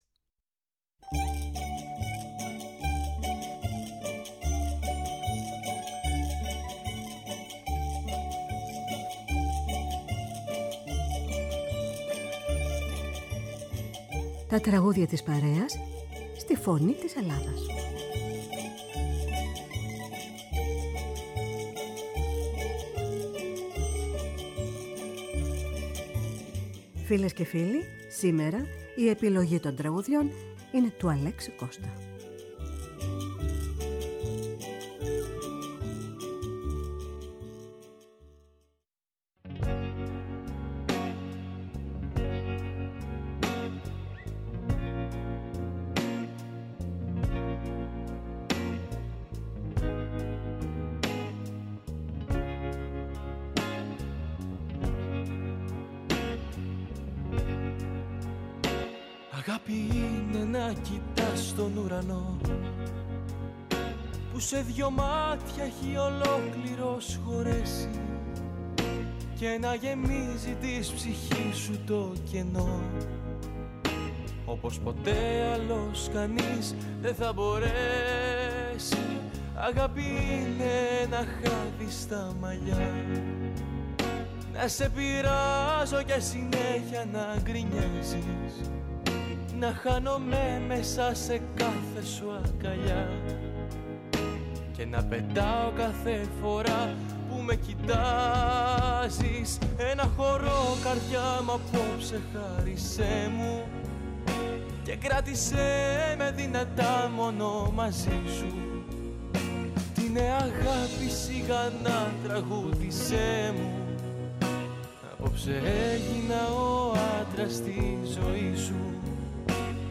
Με μουσικές από την Ελλάδα και τον κόσμο.